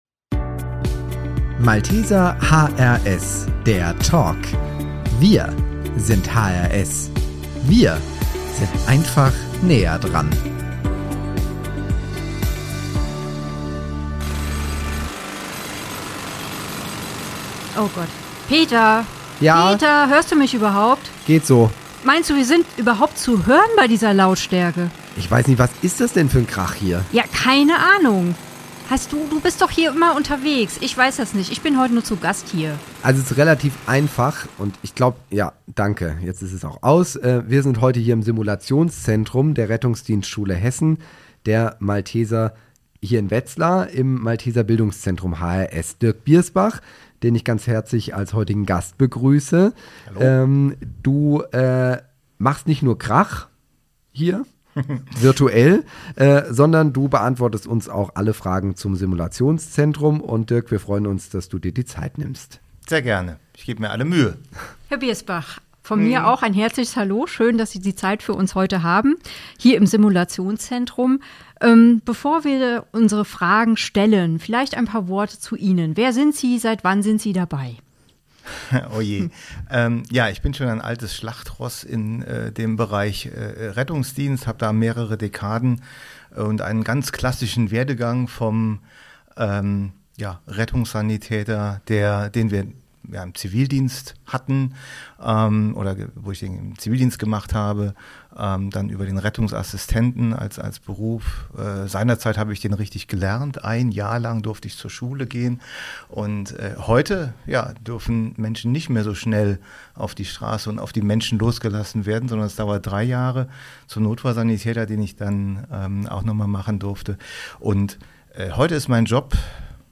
Beschreibung vor 3 Jahren In dieser Folge des Podcasts Malteser HRS, der Talk wird es laut.
Krach und Wettereinflüsse inklusive...